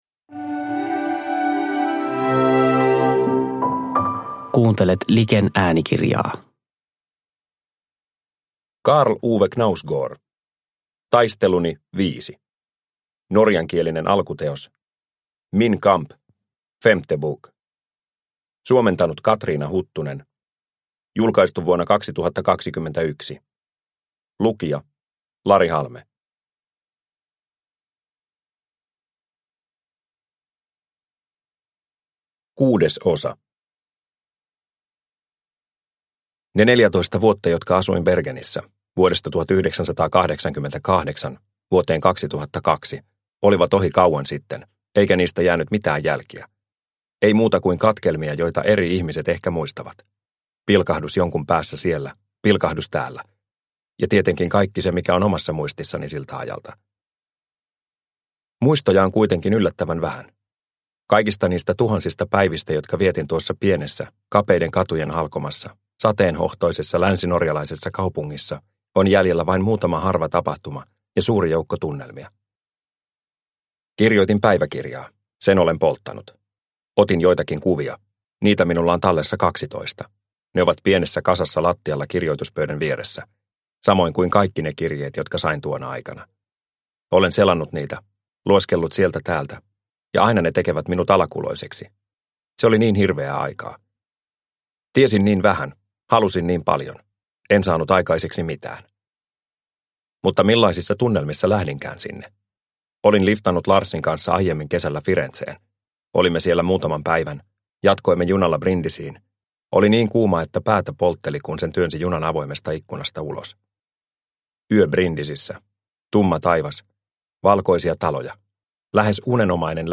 Taisteluni V – Ljudbok – Laddas ner